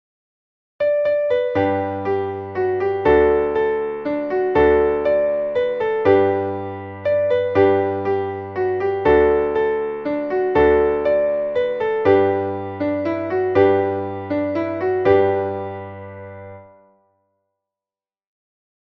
Traditionelles Lied